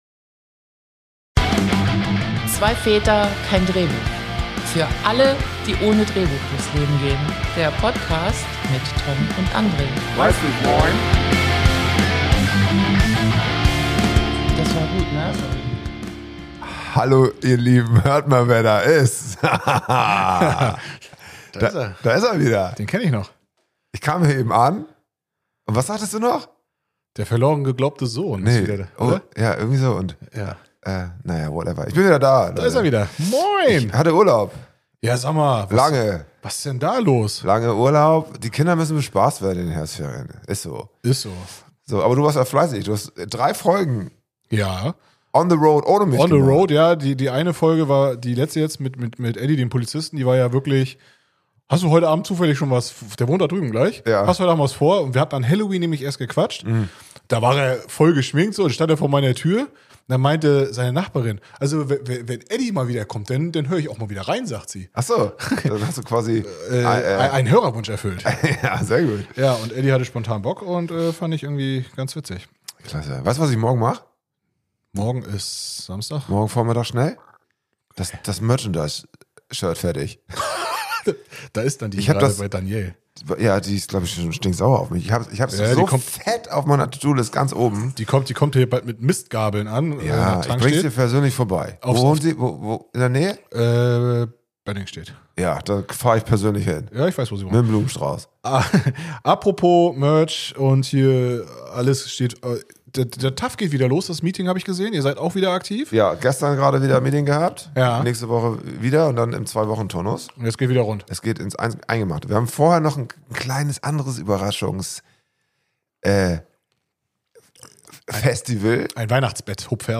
Die beiden Väter plaudern über chaotische Flugreisen mit Kindern, Strafzettel wegen 17 Sekunden Parkzeit, und warum man Belege besser doch aufbewahrt.